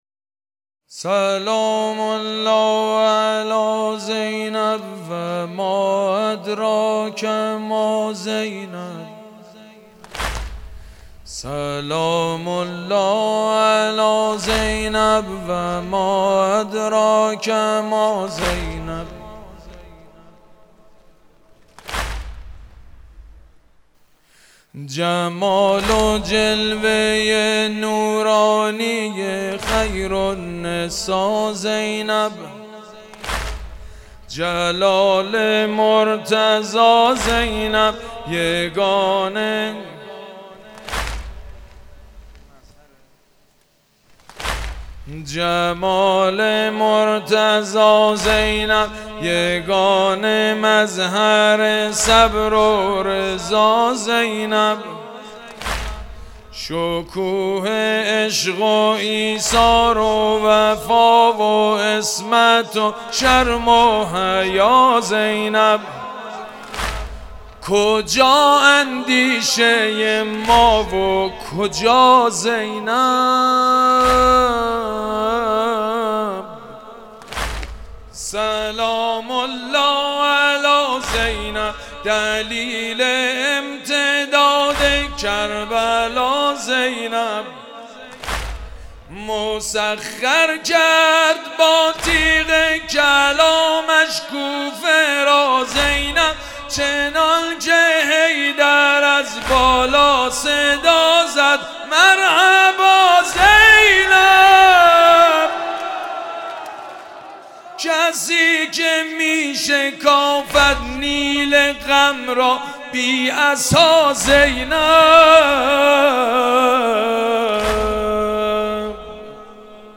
گلچین فایل صوتی سخنرانی و مداحی شب چهارم محرم، اینجا قابل دریافت است.
سید مجید بنی فاطمه - واحد